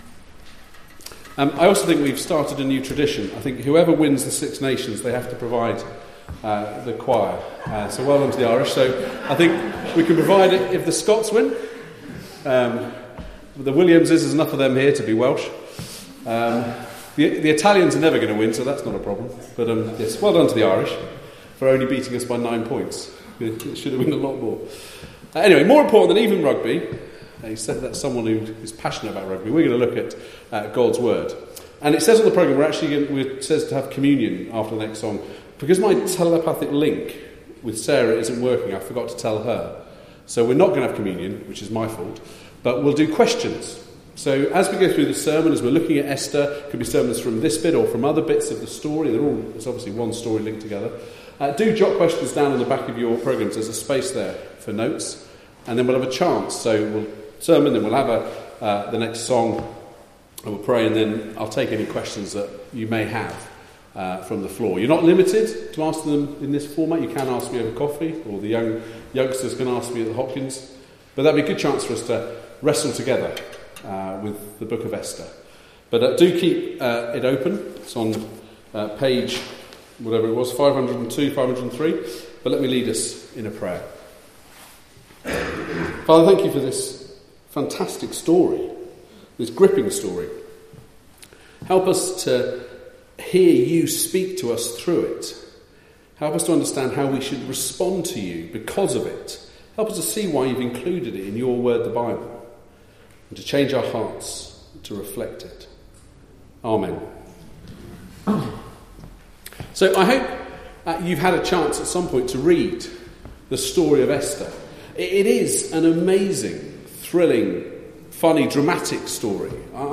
Passage: Esther 2:19 - 4:17 Service Type: Weekly Service at 4pm Bible Text